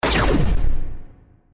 railgun.mp3